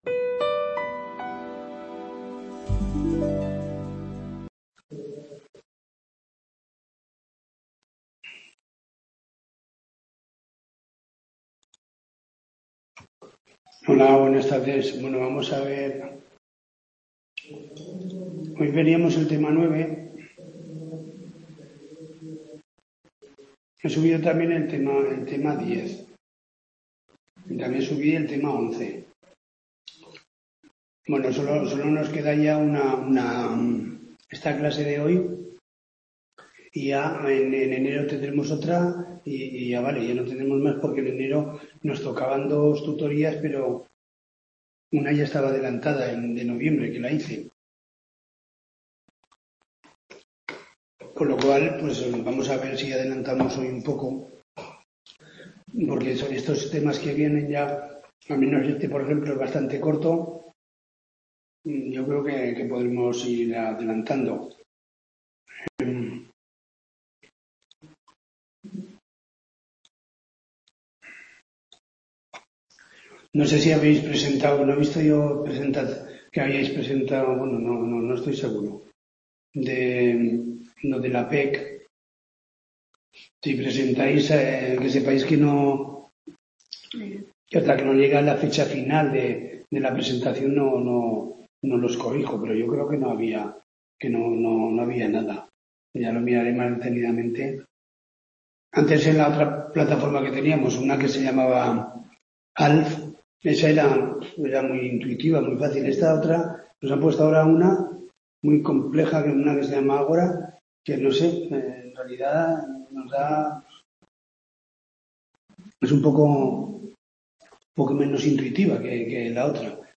TUTORÍA